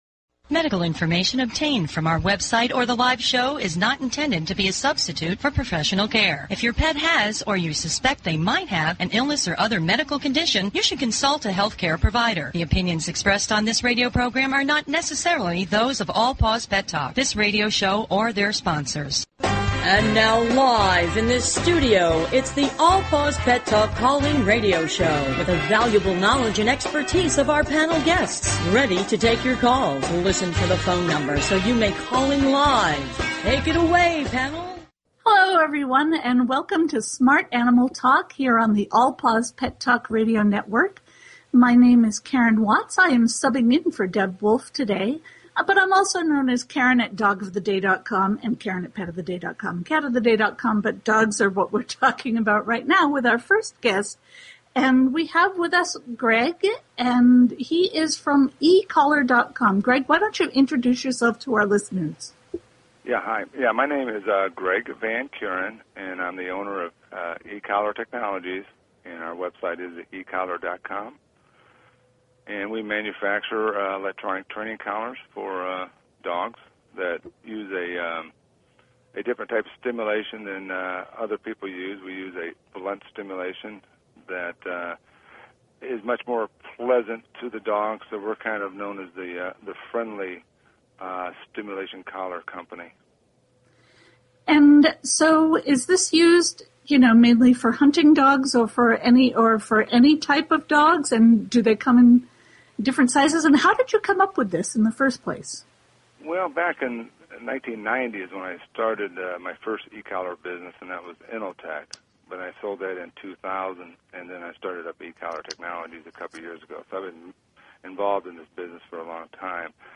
Talk Show Episode, Audio Podcast, All_Paws_Pet_Talk and Courtesy of BBS Radio on , show guests , about , categorized as
Our hosts are animal industry professionals covering various specialty topics and giving free pet behavior and medical advice. We give listeners the opportunity to speak with animal experts one on one.